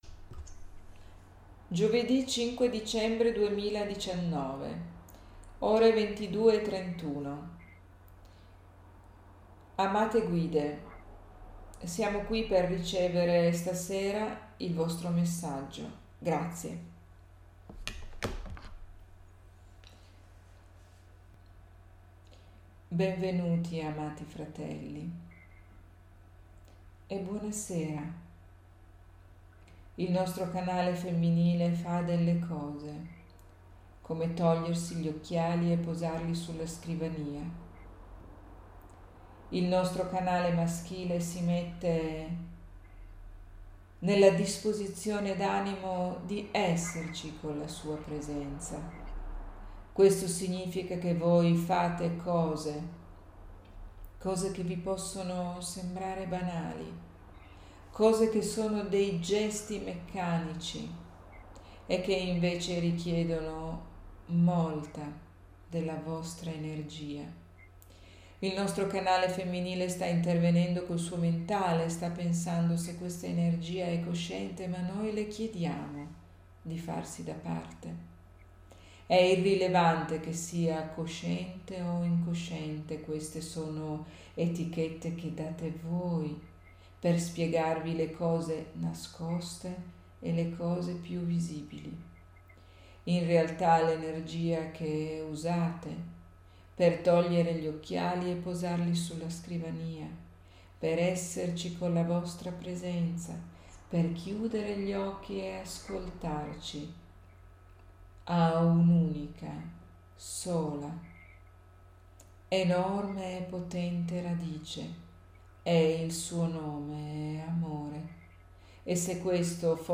Messaggio del 5 dicembre 2019 trasmesso in diretta streaming durante il webinar Qui il video integrale del webinar
[clicca e ascolta la registrazione integrale della sessione di channeling]